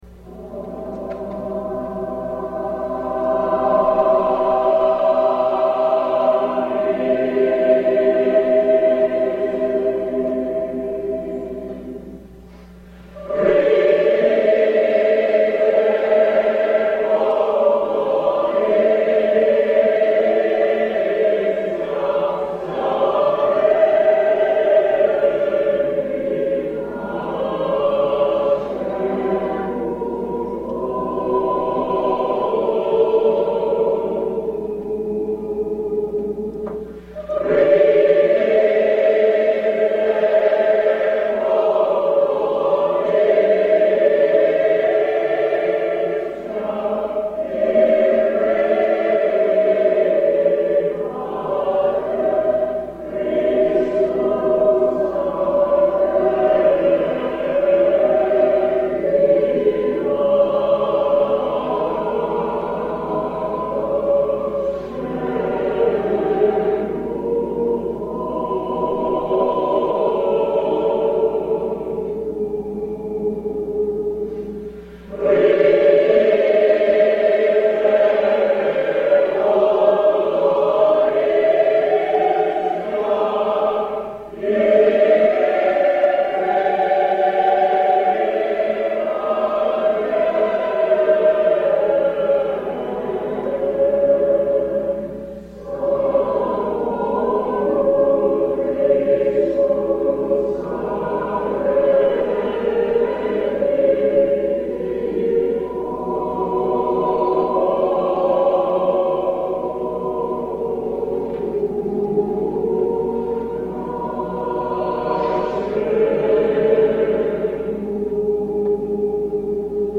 Chorproben MIDI-Files 502 midi files
Stimmen - MIDI / mp3 (Chor) mp3
S A T B